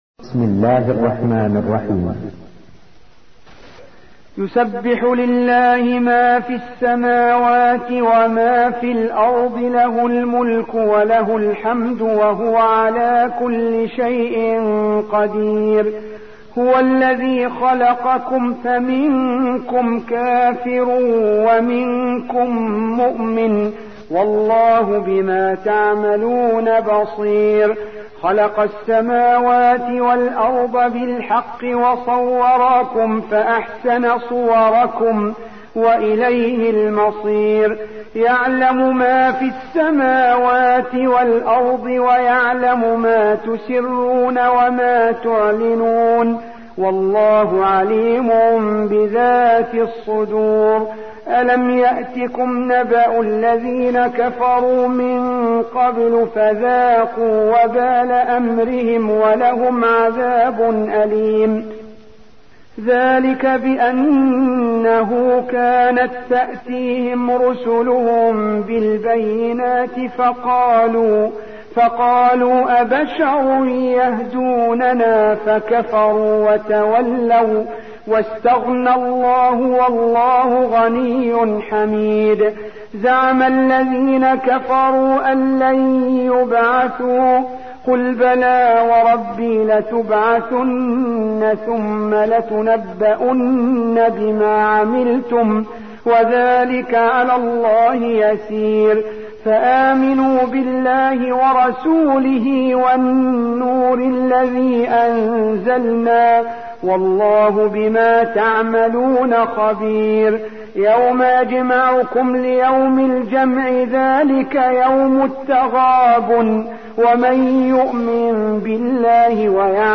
64. سورة التغابن / القارئ